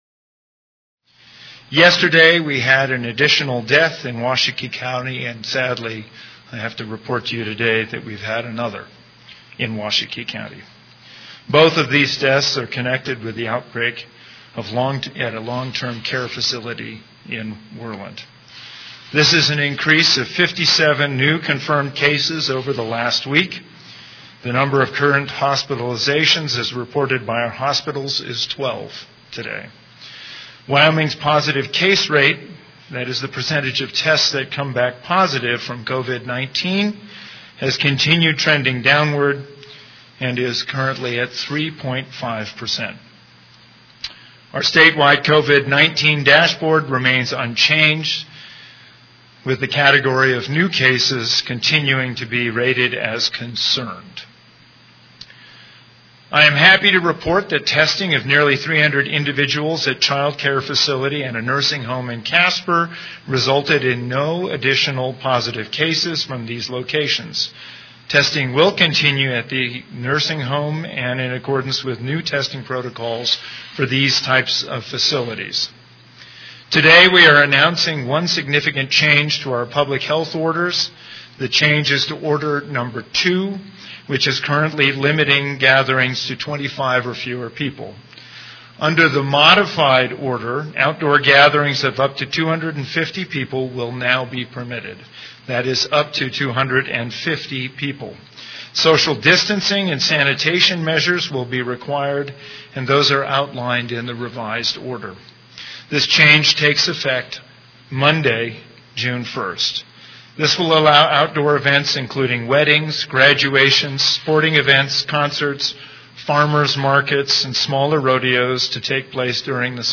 Click on this link to listen to the audio of the briefing .